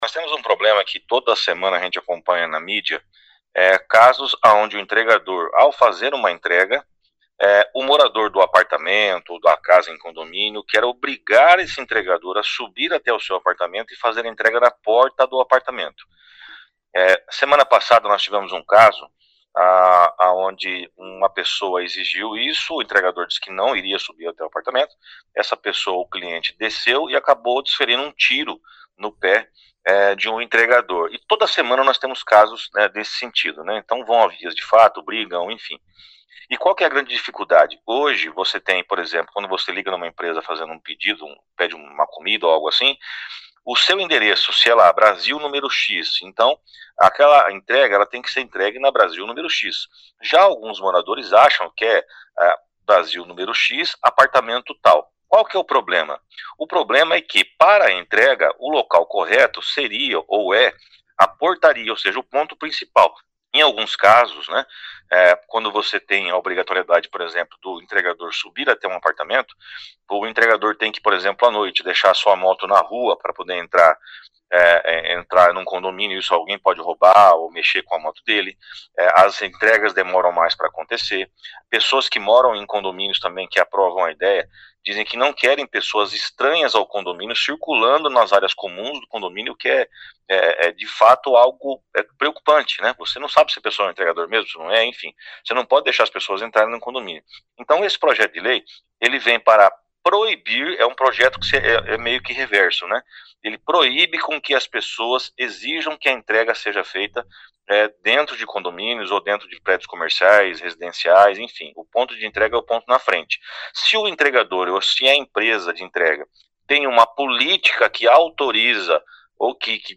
A proposta é do vereador Flávio Mantovani.
Ouça o que diz o autor do projeto de lei: